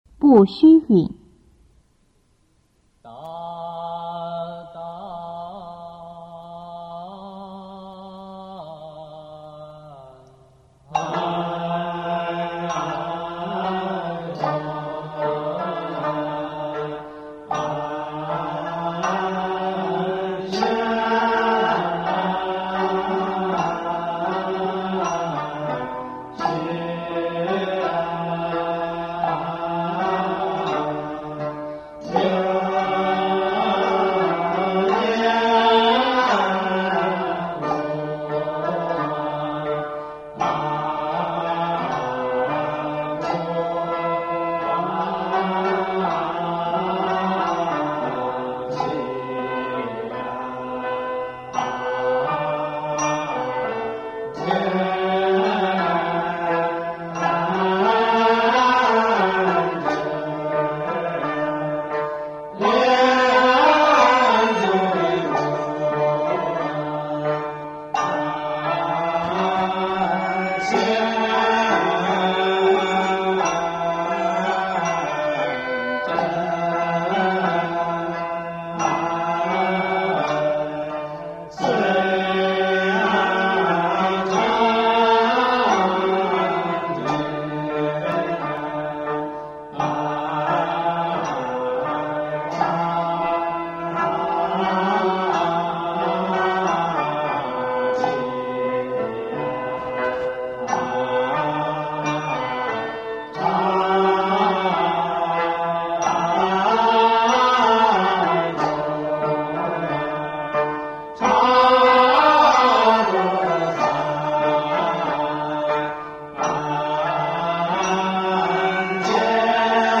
中国道教音乐 全真正韵 步虚韵 - 道音文化
简介：斋醮科仪时，道众旋绕坛场时虚声诵唱的韵腔，象征将往玉京金阙朝拜圣真时，绕行于虚空之中。词语玄妙，行腔圆润，有一种规律起伏的飘渺感。
天宝十年(751年)，唐玄宗还在宫廷内道场中，亲自教诸道士步虚声韵，此歌为五言诗体，单句反复体。前后加散板引腔和尾腔，终止式与《澄清韵》相同。